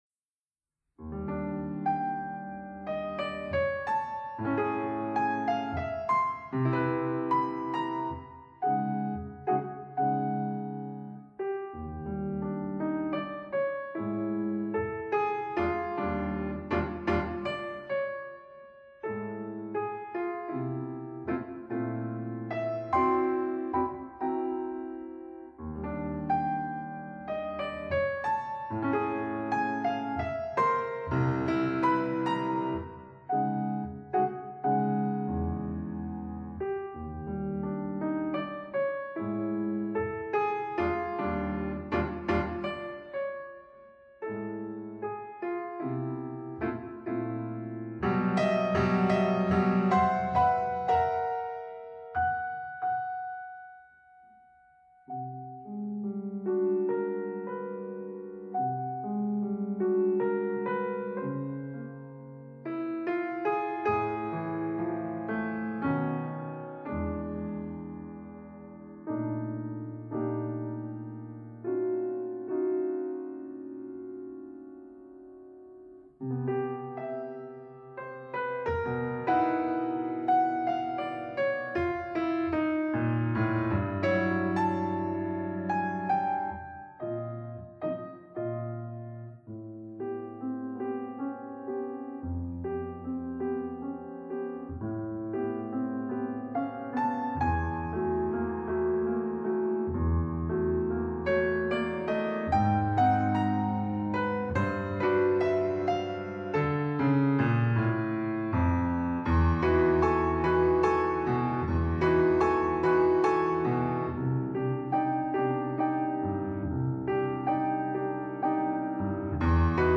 I. Andante - 2:29, II. 3:26, III. 4:30.
pianista